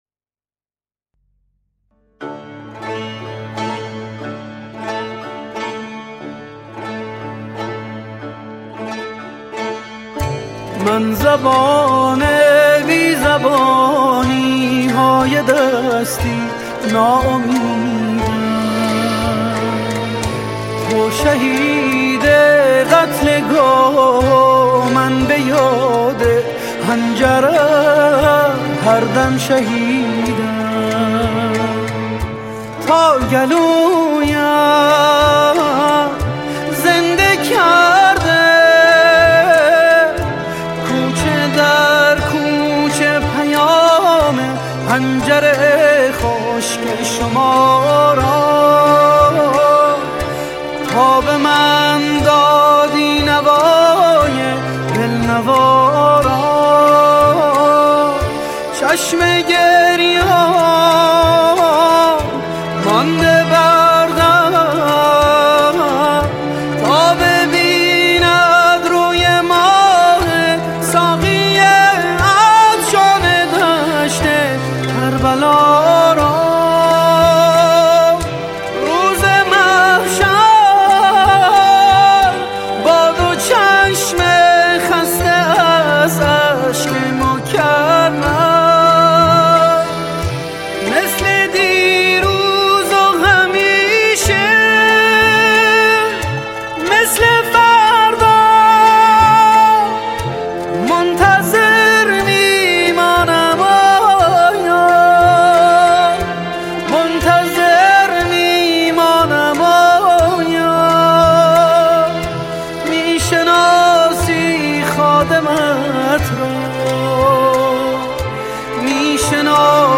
غمگین قدیمی